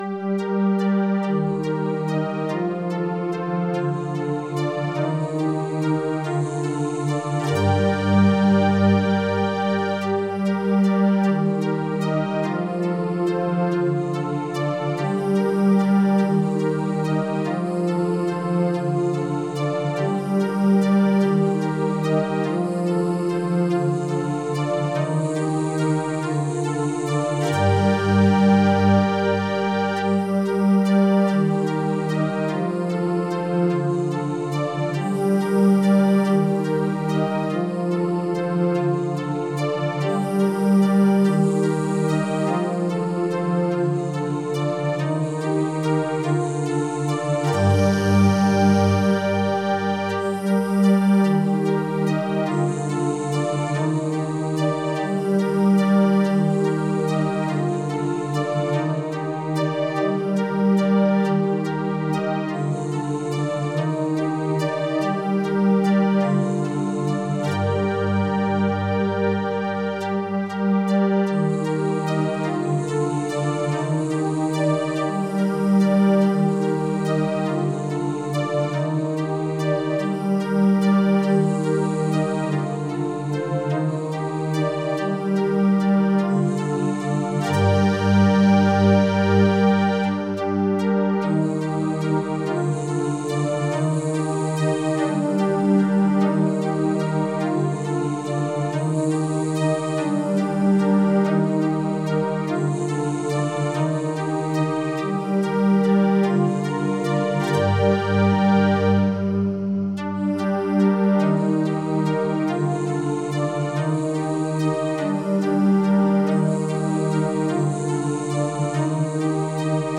I added piano and guitar/mandolin chords (the text names, like Gm, Dm, etc...) to my latest tune.
Attached you will find the backing track.
Soliloquy-in-G-minor-Backing-Track.mp3